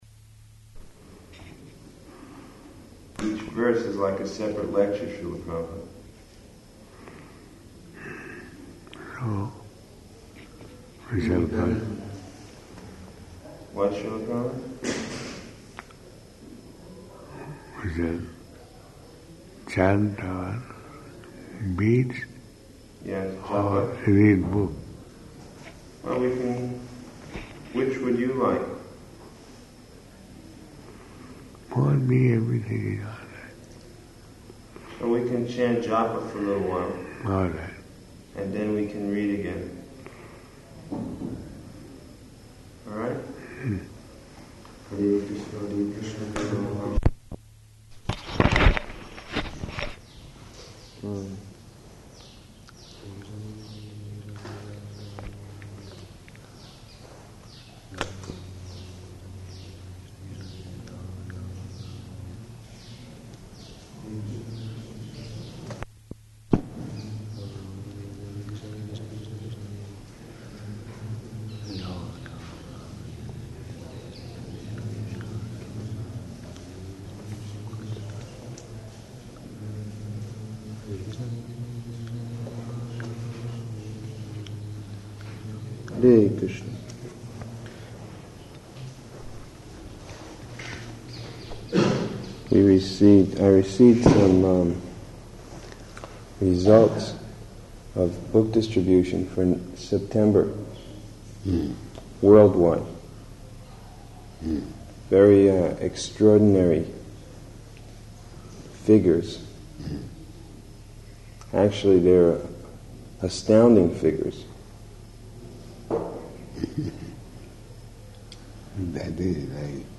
Room Conversation
Location: Vṛndāvana